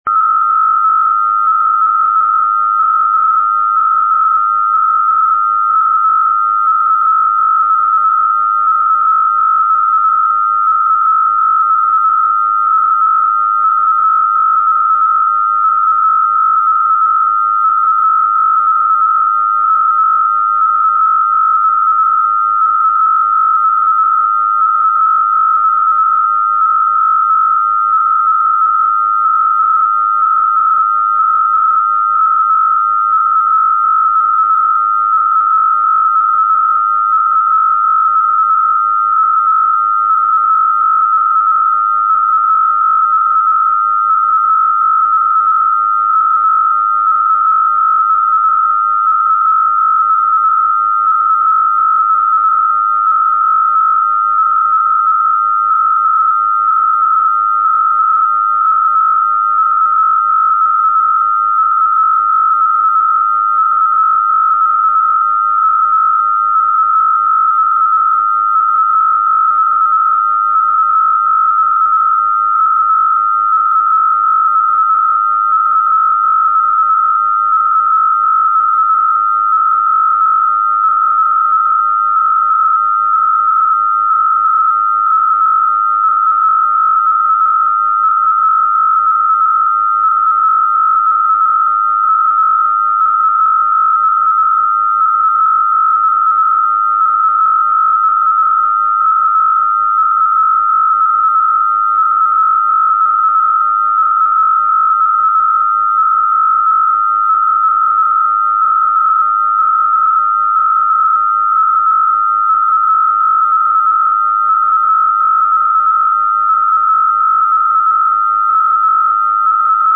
Hellschreiben = HELL